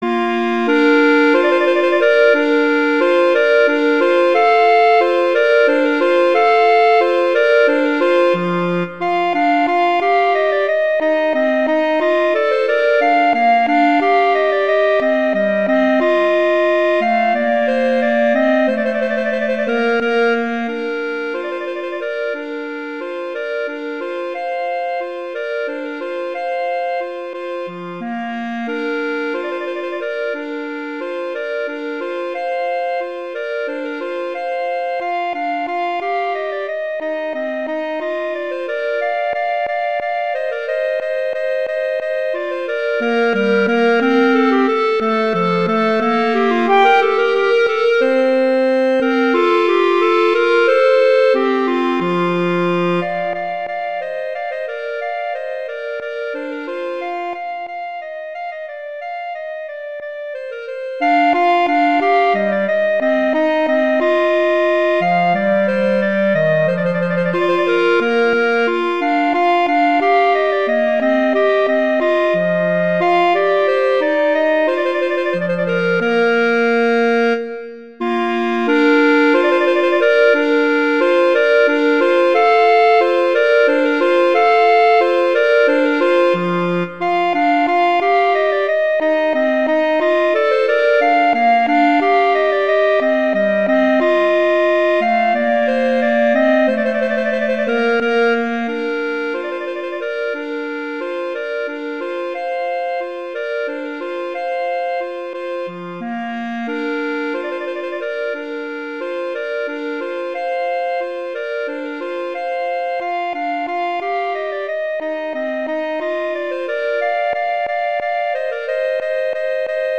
classical, wedding, festival, love
Bb major
♩=180 BPM (real metronome 176 BPM)